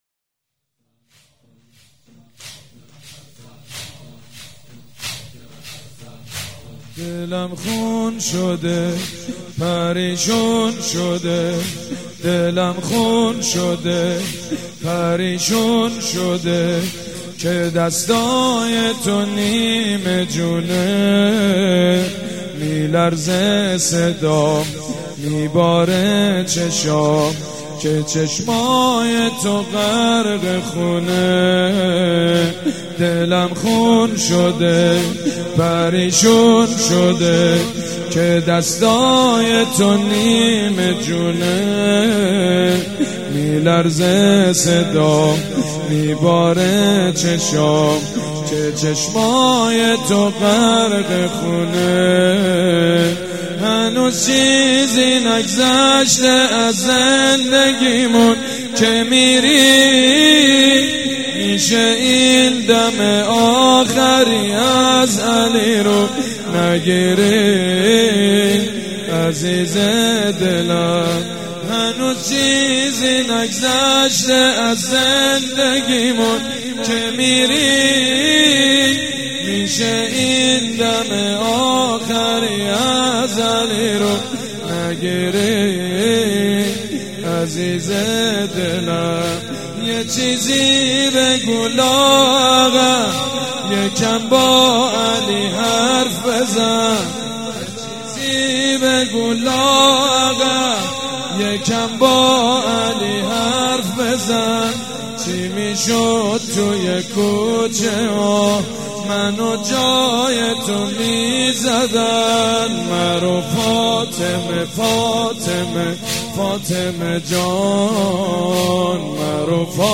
شب هفتم رمضان95
زمینه، روضه، مناجات